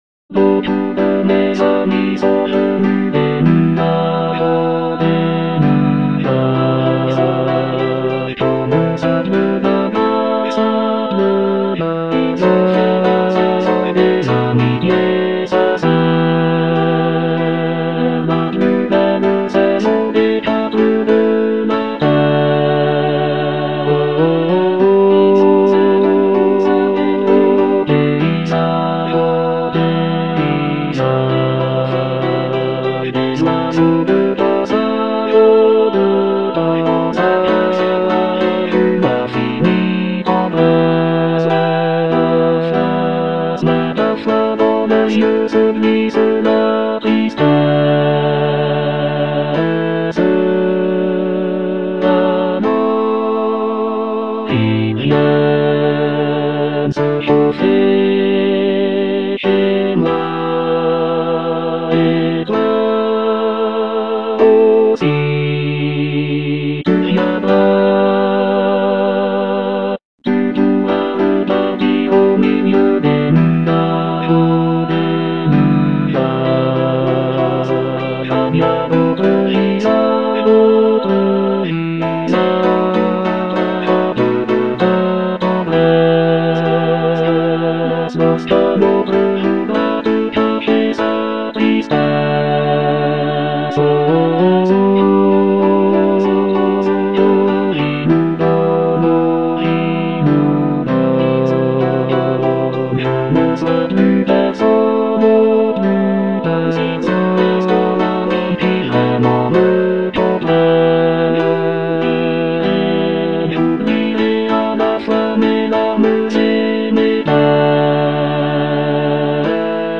Bass II (Emphasised voice and other voices)
for choir